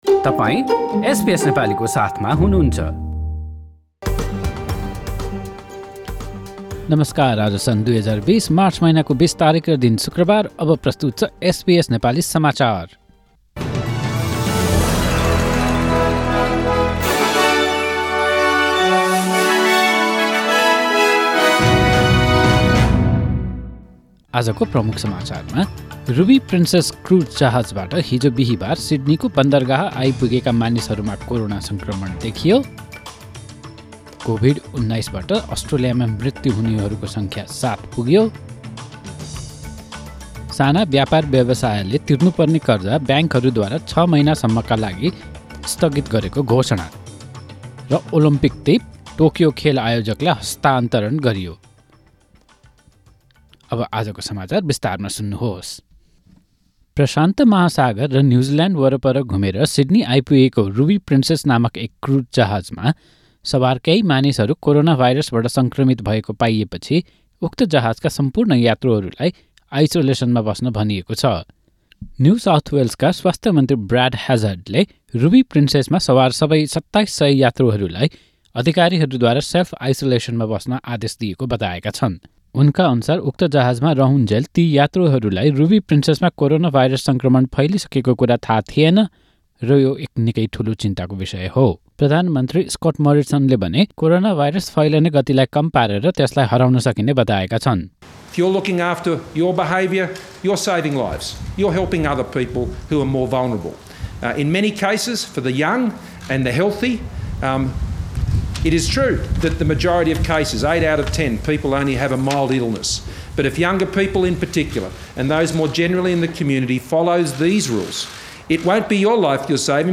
Listen to the latest news headlines in Australia from SBS Nepali radio